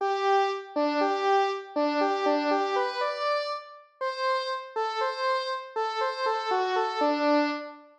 eine-kleine-cello-1.wav